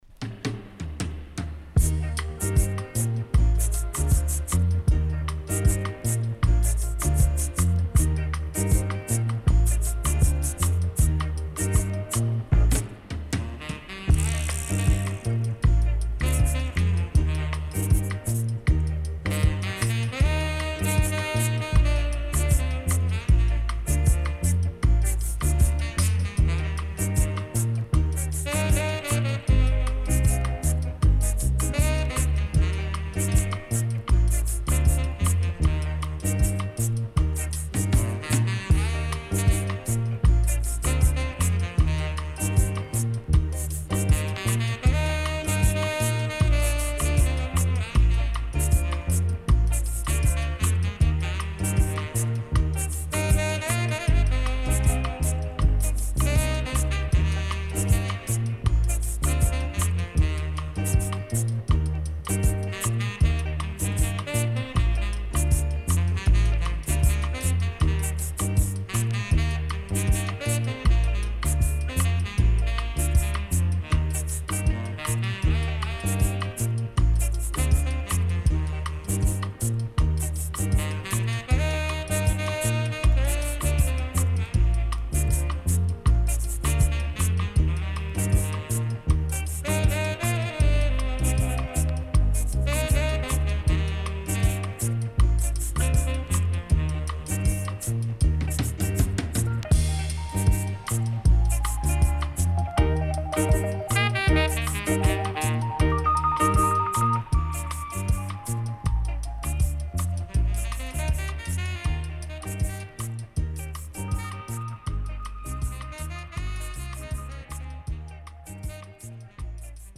Jazzy Sax Inst.W-Side Good
SIDE A:少しチリノイズ入ります。